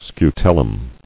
[scu·tel·lum]